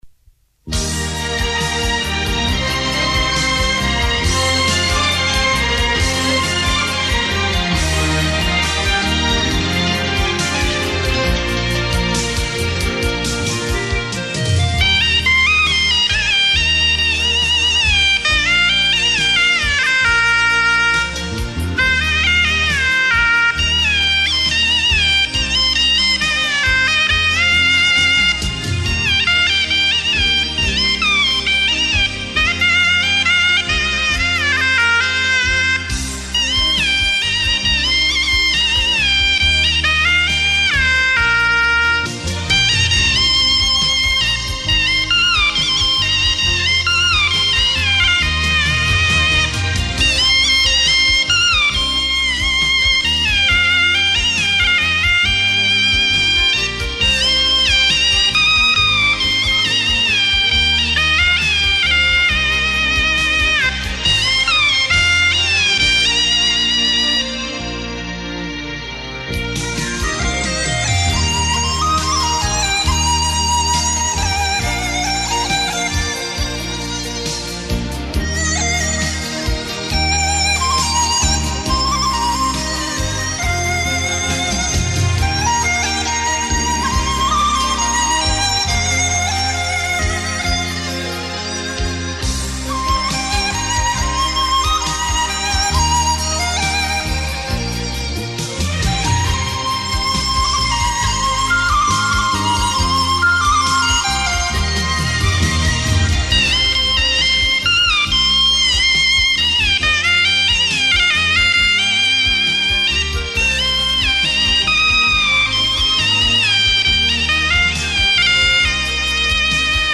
[4/4/2010]大地珍藏，唢呐金曲【鱼水情】